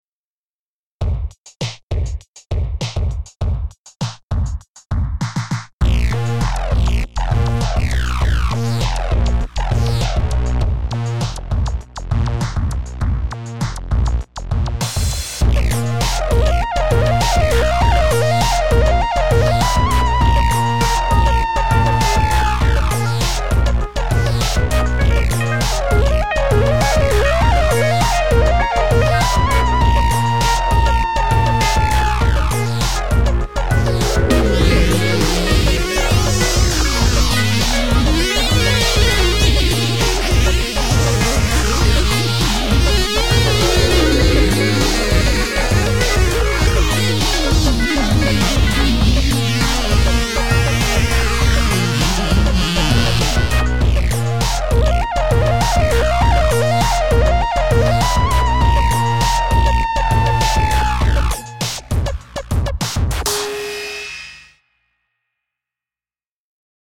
ManyOne comes with a wide range of sounds in four categories: electric pianos, ensembles, organs, and waveforms (which include classic analog waves) that are all selectable from the easy to use sound browser.
Demo made with ManyOne sounds only